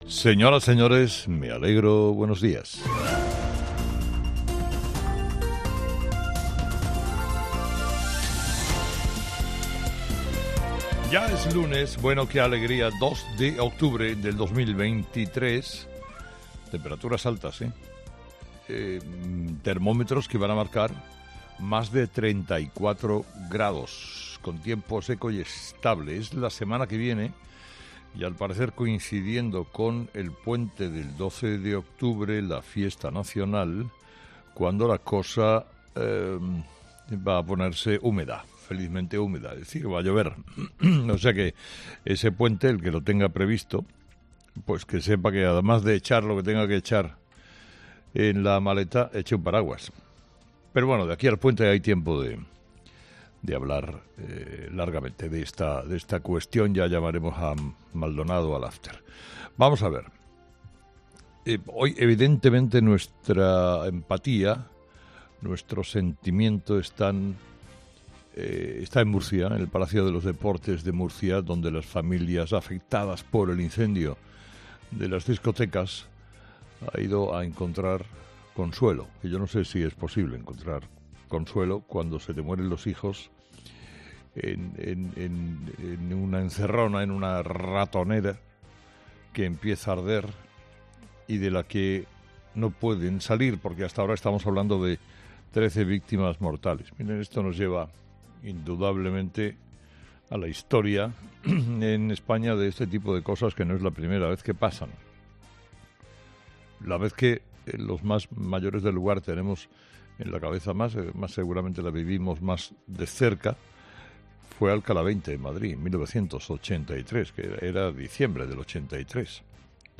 Carlos Herrera repasa los principales titulares que marcarán la actualidad de este lunes 2 de octubre